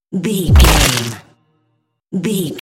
Dramatic hit deep wood
Sound Effects
Atonal
heavy
intense
dark
aggressive